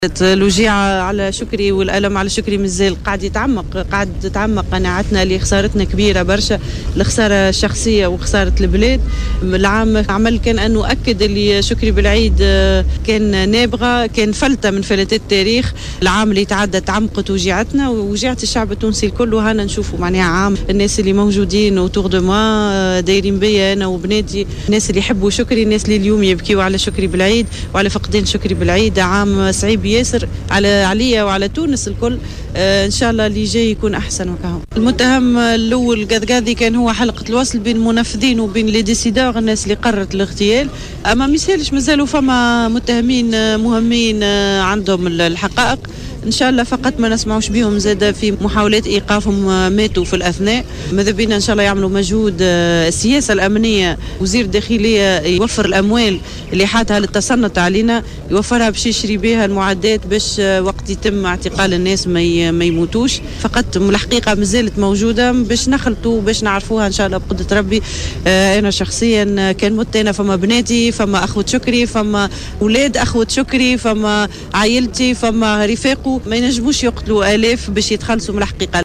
Basma Khalfaoui, veuve de Chokri Belaïd, a déclaré lors de son passage sur les ondes de Jawhara FM, jeudi 6 février 2014, que le ministre de l’intérieur doit investir dans des équipements permettant d’arrêter les assassins avant leur passage à l’acte, au lieu des équipements d’espionnage des tunisiens.